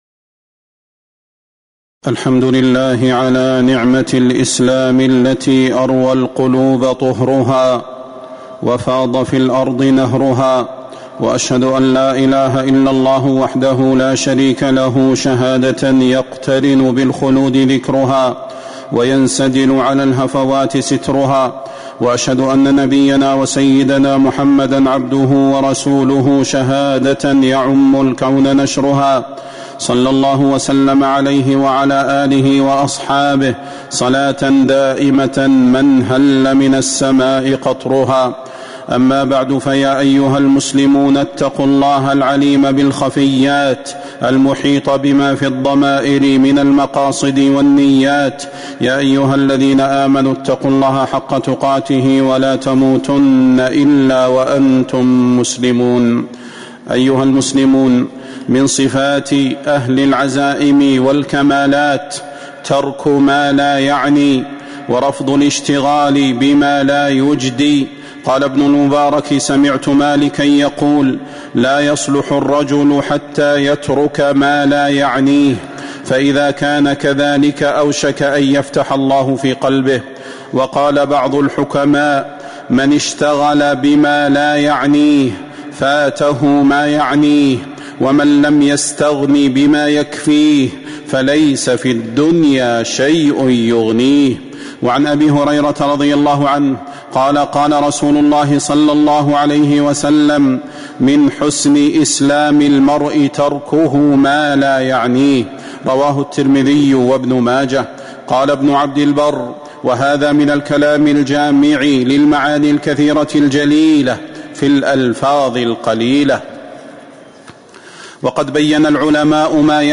تاريخ النشر ٤ ذو القعدة ١٤٤٦ هـ المكان: المسجد النبوي الشيخ: فضيلة الشيخ د. صلاح بن محمد البدير فضيلة الشيخ د. صلاح بن محمد البدير من حسن إسلام المرء تركه مالا يعنيه